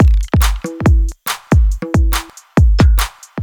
Category 🎵 Music
beat beats drumkit fast Gabber hardcore House Jungle sound effect free sound royalty free Music